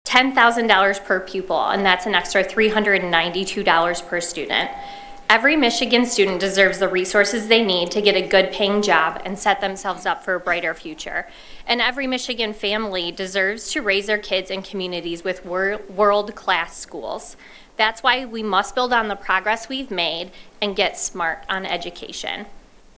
State budget director Jen Flood presented the spending plan to lawmakers, which includes record spending for public schools.